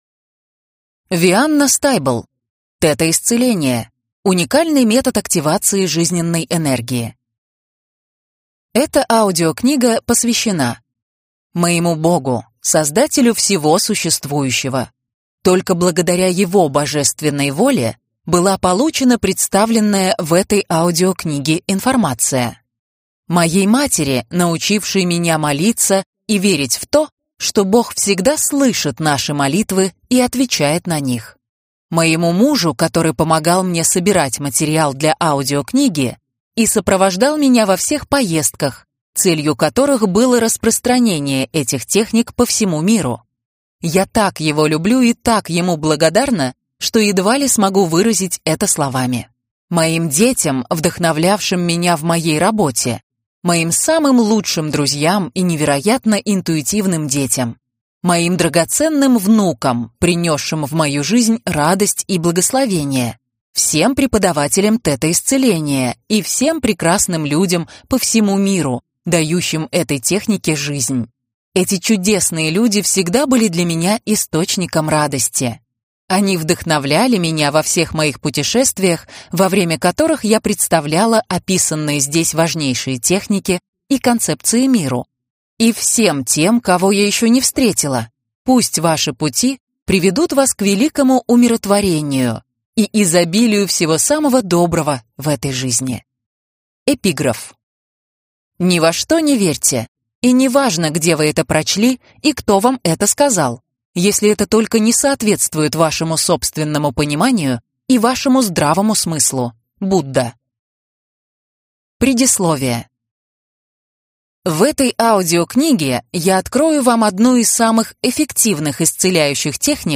Аудиокнига Тета-исцеление. Уникальный метод активации жизненной энергии | Библиотека аудиокниг